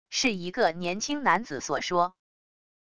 是一个年轻男子所说wav音频